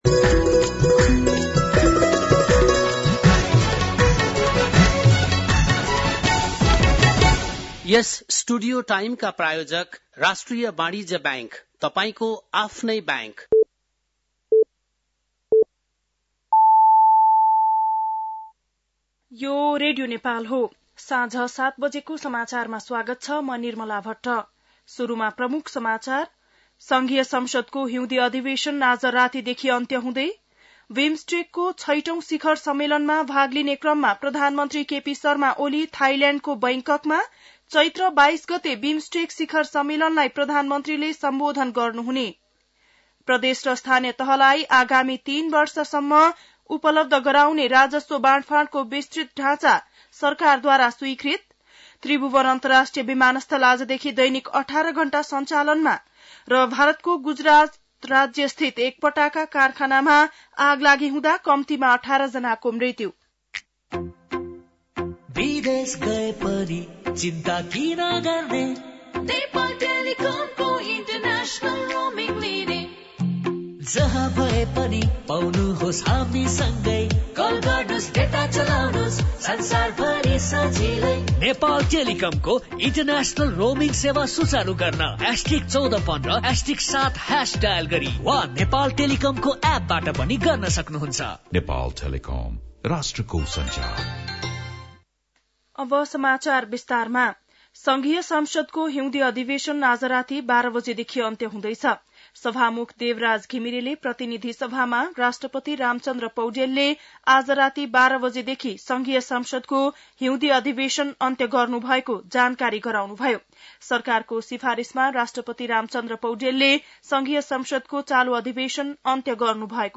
बेलुकी ७ बजेको नेपाली समाचार : १९ चैत , २०८१